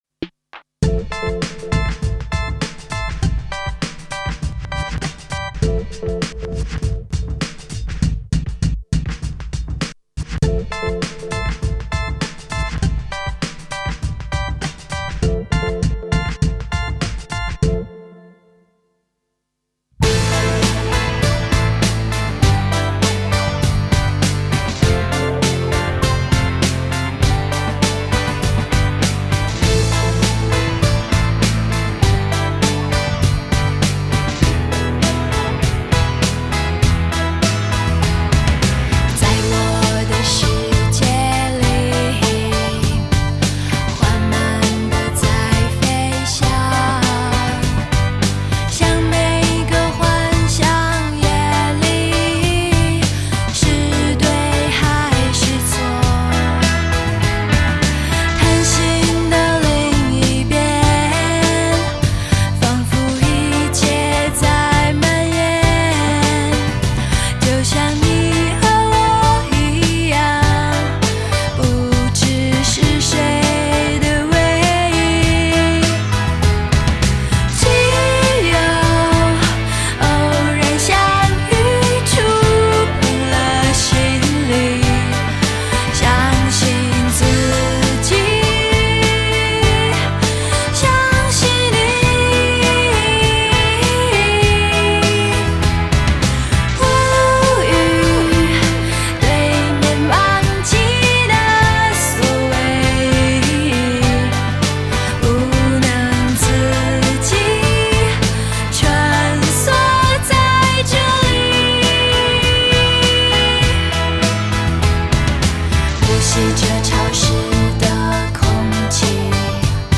【类别】 内地流行